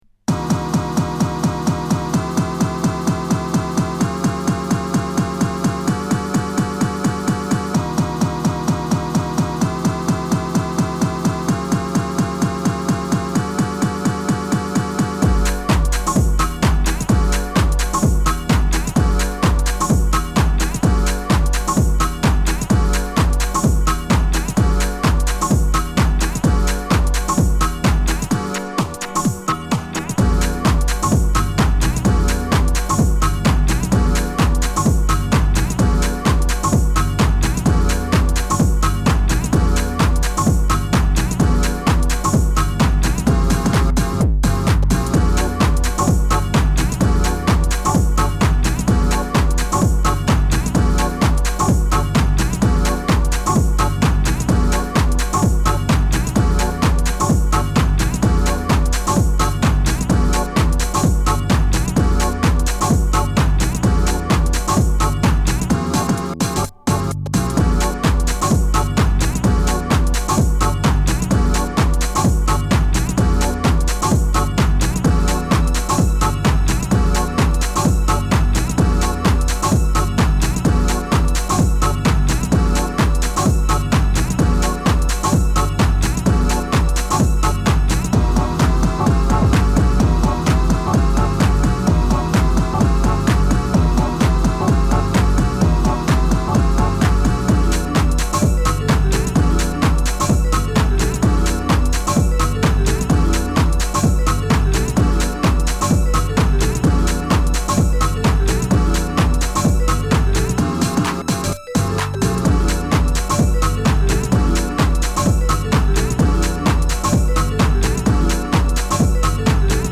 Chicago House , Deep House